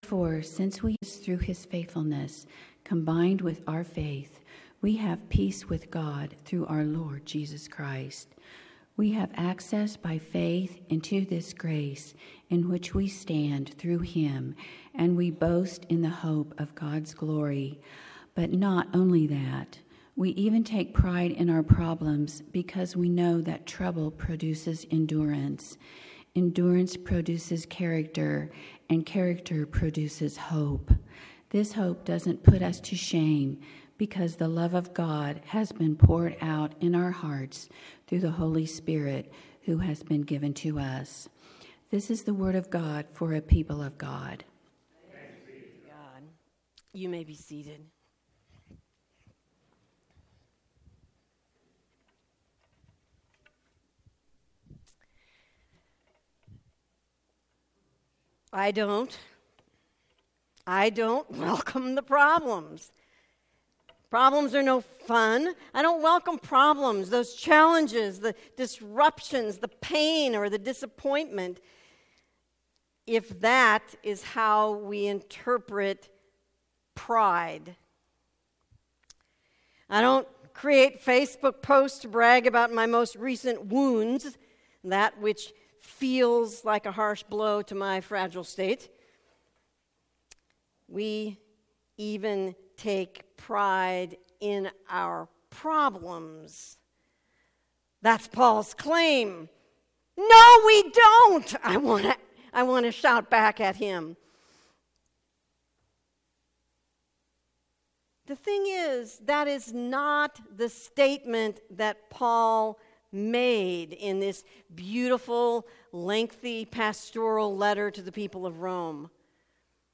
Shawnee Heights United Methodist Church Sermons